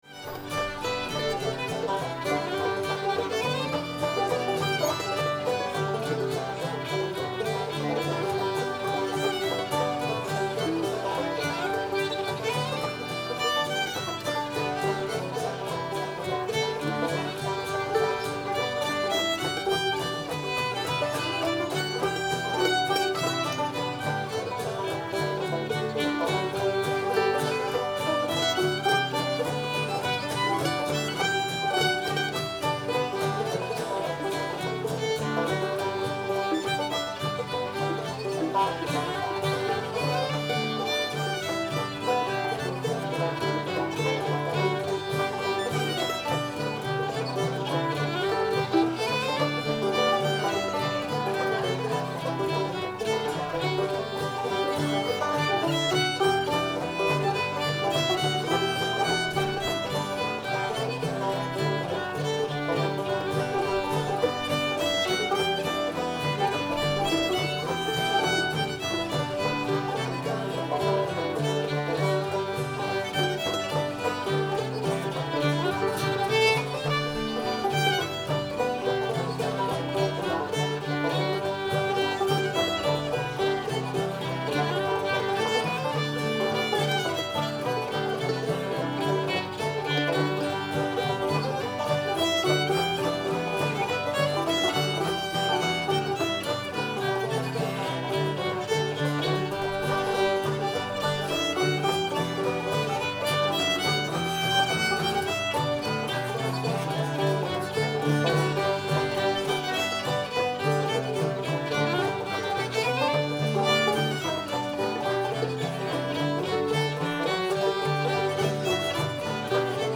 girl i left behind me [G]